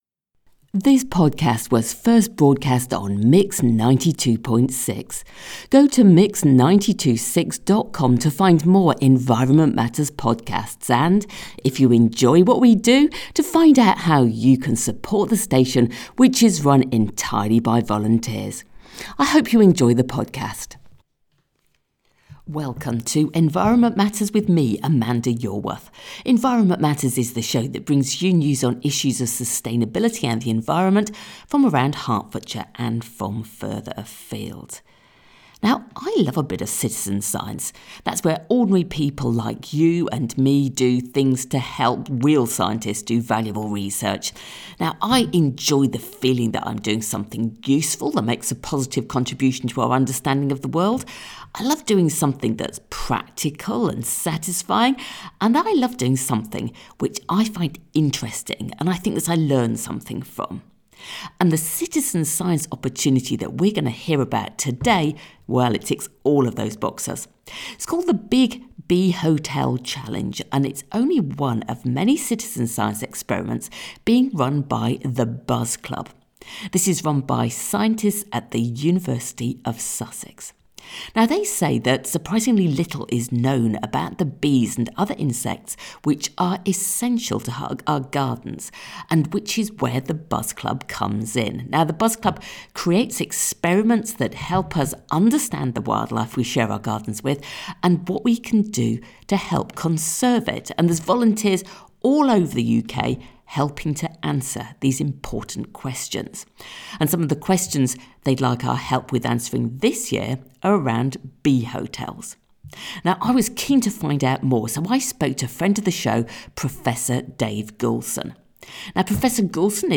In this week’s Environment Matters podcast, Professor Dave Goulson of Sussex University explains why he wants all of us to join in the Big Bee Hotel Challenge, and what you’ll need to do to take part.